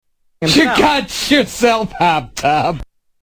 Category: Comedians   Right: Personal
Tags: Comedian Gilbert Gottfried Actor Stand-up comedian Gilbert Gottfried audio clips